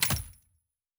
pgs/Assets/Audio/Fantasy Interface Sounds/Locker 3.wav at master
Locker 3.wav